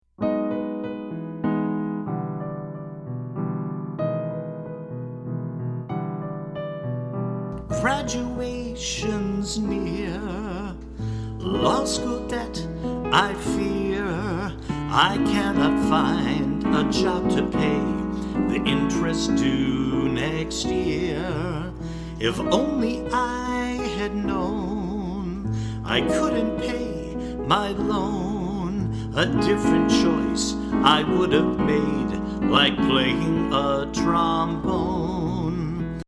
musical parodies